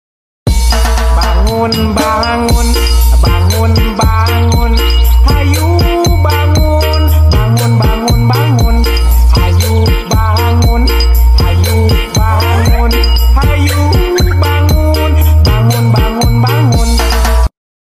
nada dering ringtone notifikasi WhatsApp